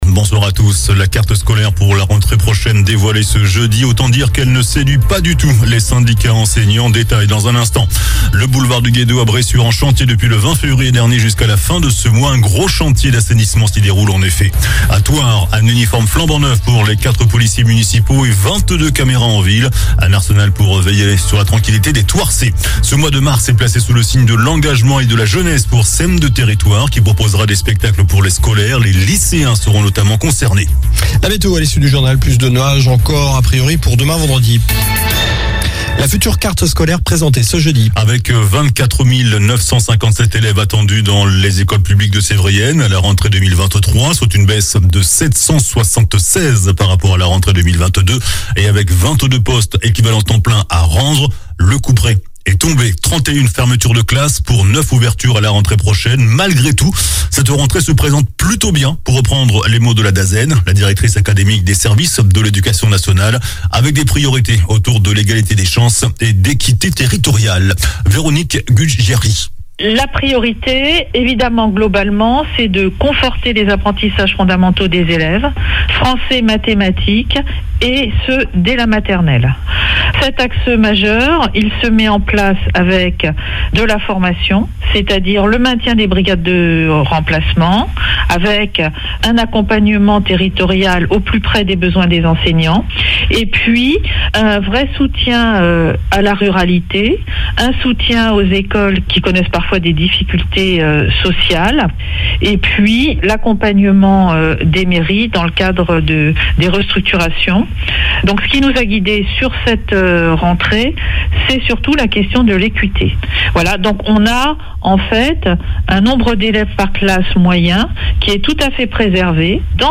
JOURNAL DU JEUDI 02 MARS ( SOIR )